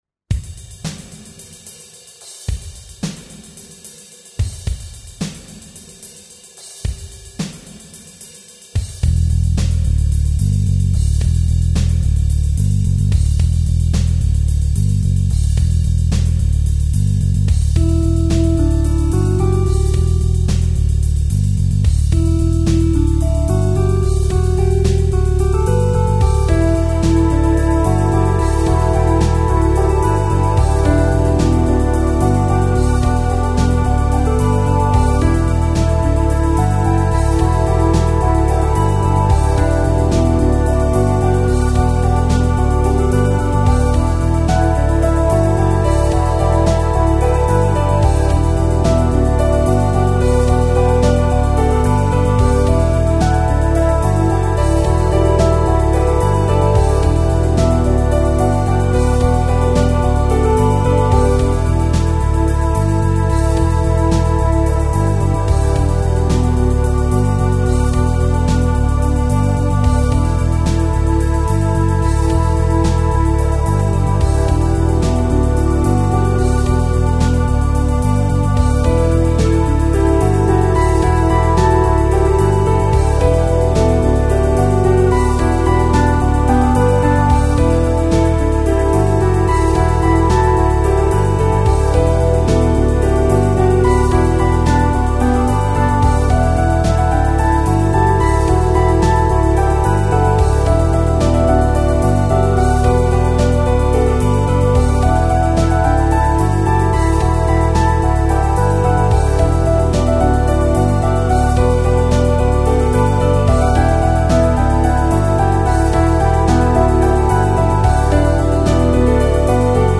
Melodic Rocker